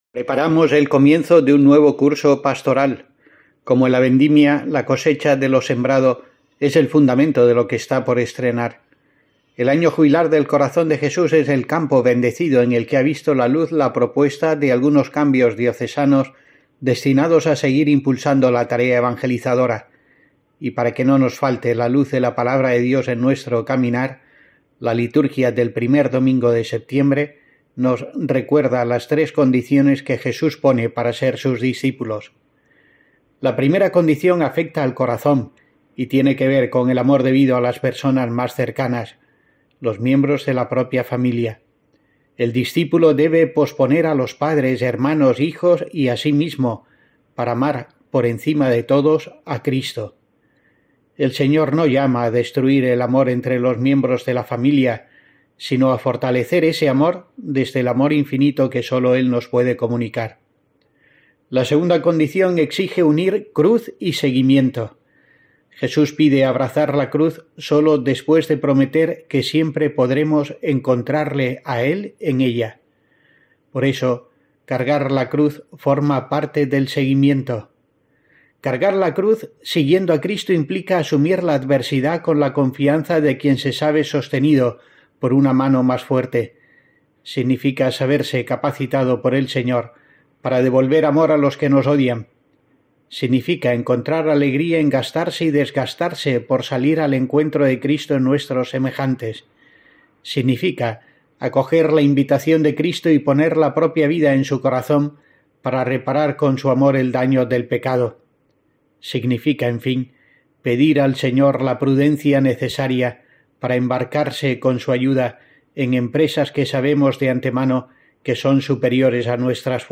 Escucha aquí la primera reflexión de monseñor José Rico Pavés para los oyentes de COPE en el curso pastoral 2022-23